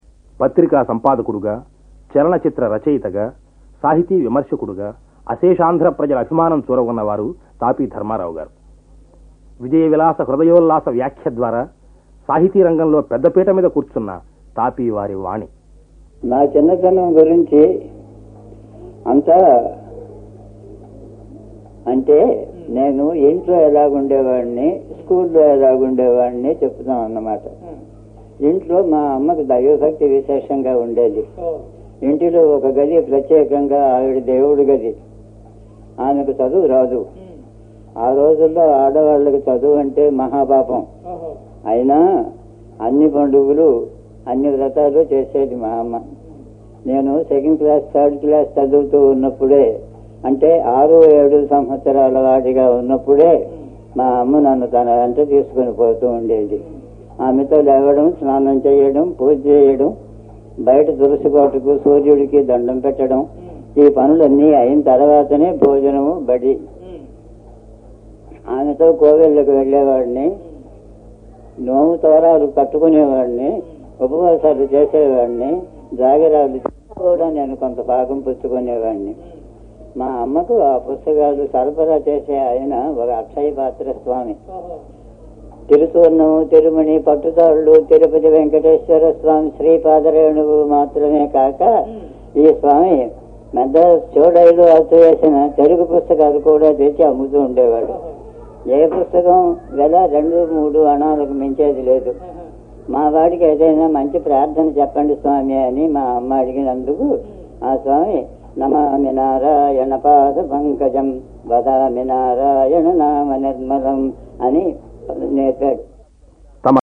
ఈ గొంతుకలన్నీ ఆకాశవాణి వారు వేరువేరు సందర్భాల్లో రికార్డు చేసినవి.
రచయిత, పత్రికా సంపాదకుడు, సాహితీ విమర్శకుడు తాపీ ధర్మారావు.